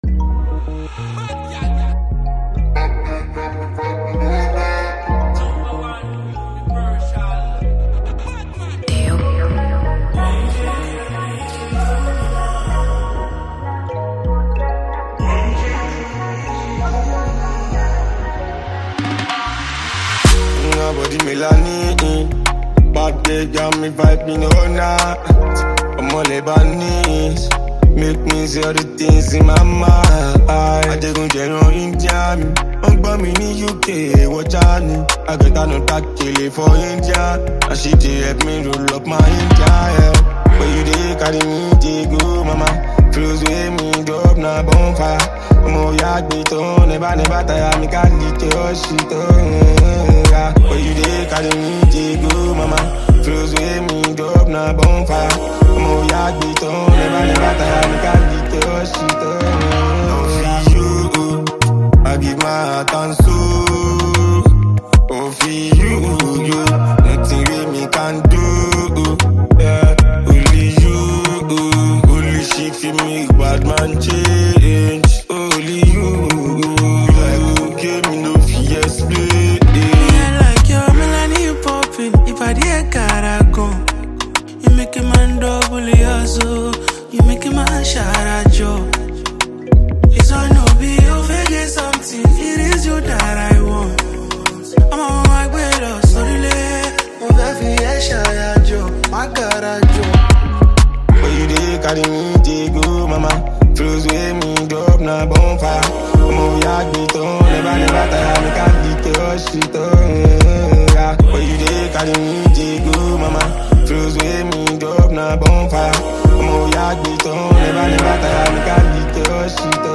a warm and soulful Afrobeats record